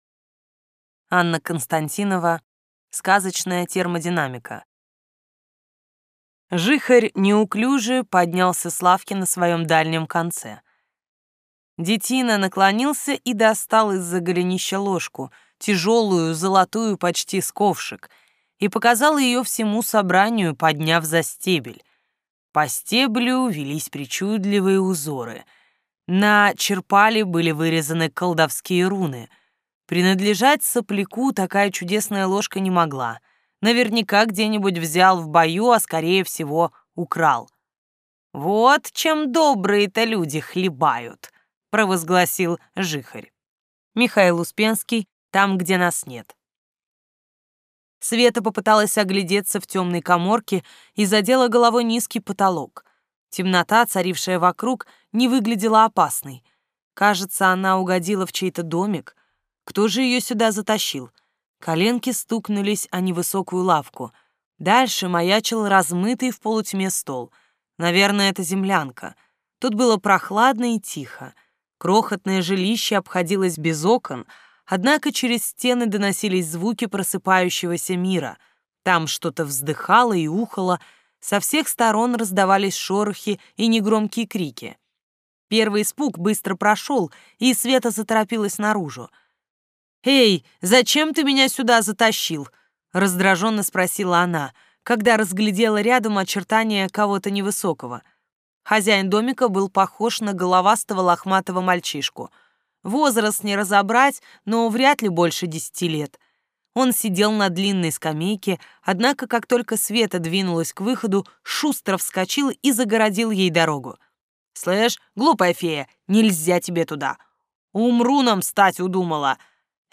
Аудиокнига Сказочная термодинамика. Часть 2 | Библиотека аудиокниг